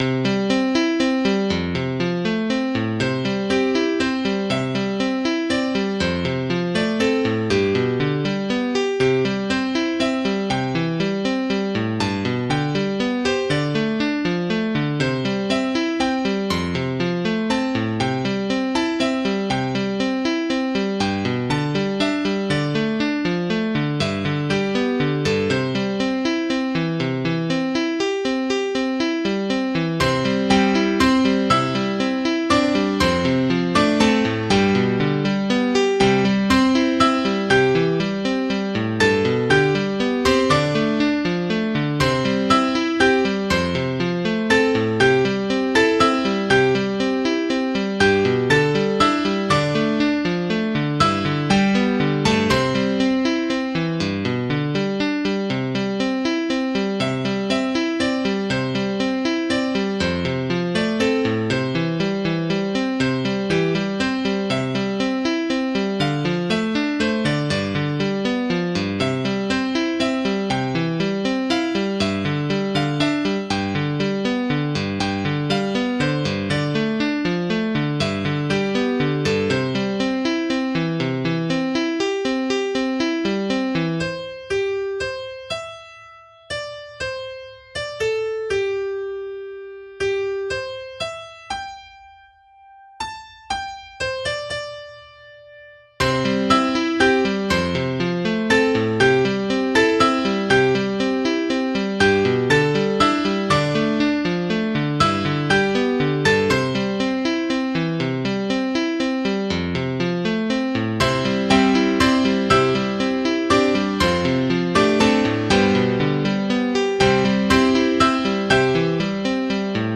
MIDI 5.65 KB MP3 (Converted)